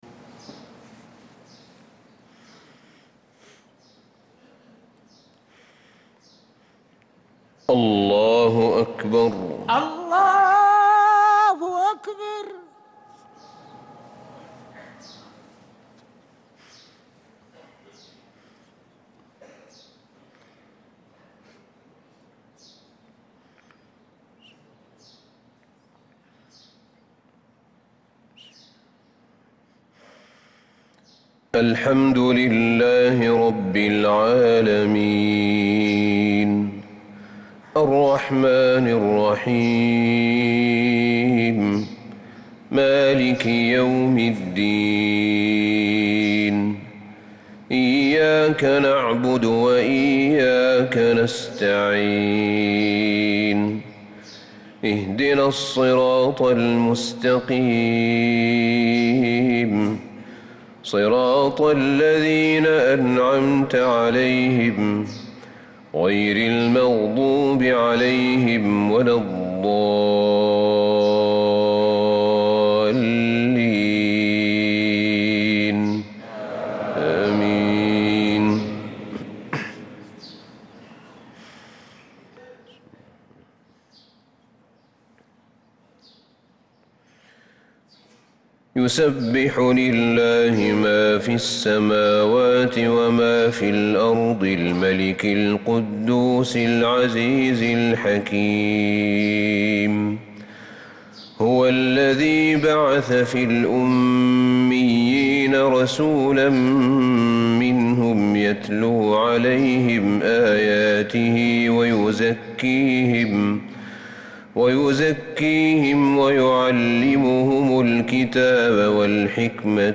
صلاة الفجر للقارئ أحمد بن طالب حميد 27 محرم 1446 هـ
تِلَاوَات الْحَرَمَيْن .